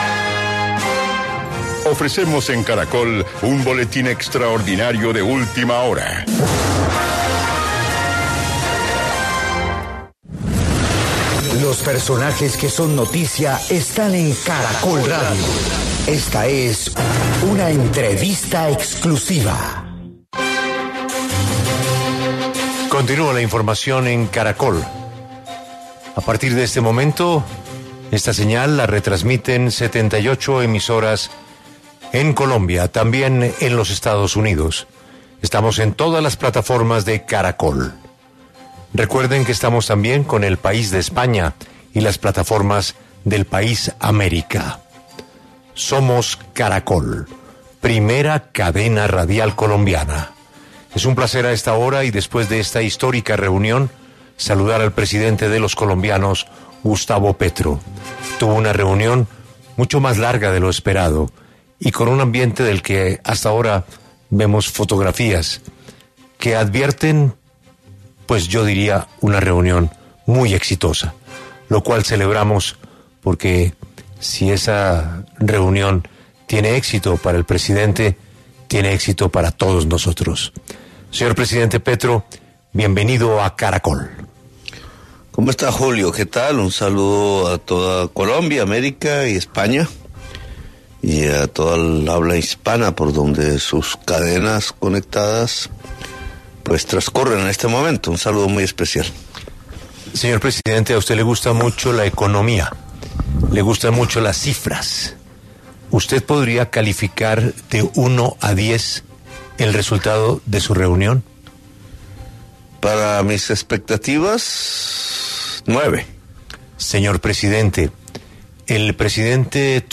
Presidente Gustavo Petro habla en exclusiva con Caracol Radio tras reunión con Donald Trump